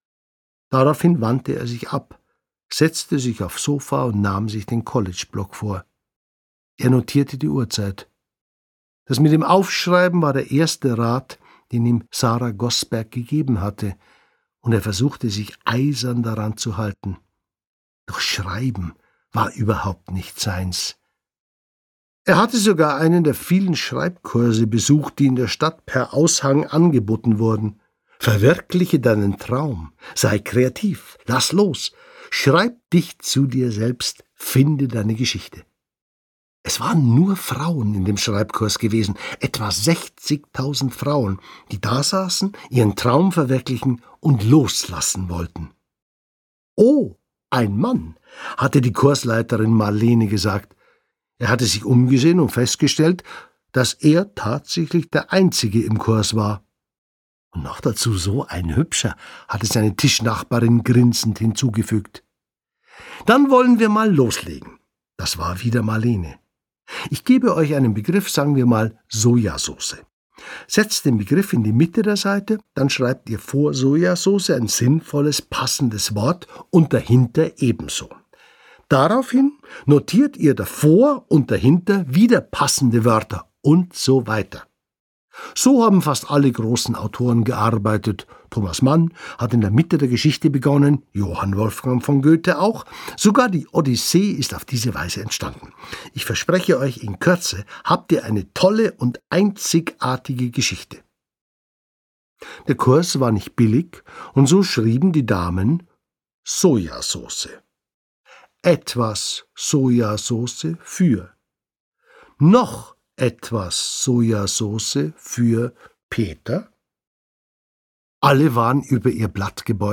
Jörg Maurer spricht
Er hat eine sehr angenehme Stimme, die genau zu der Geschichte passt.